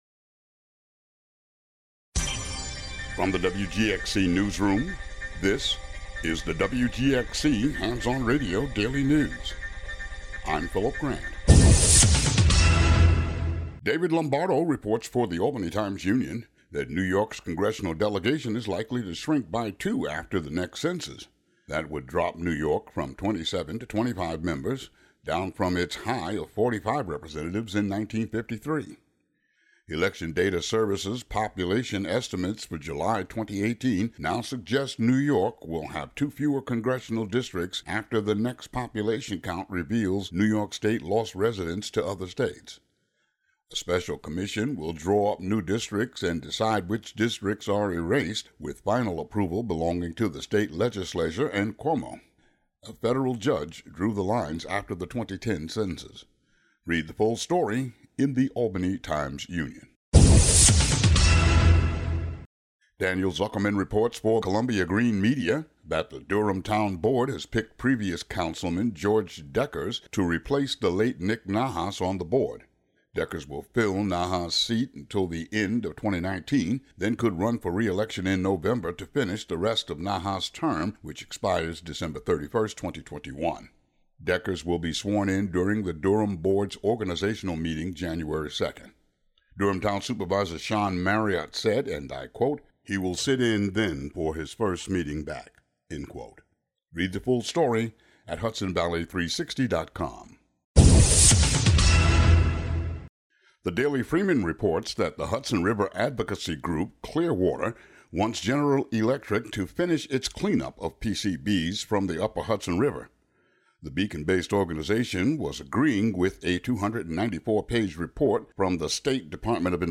Today's local news.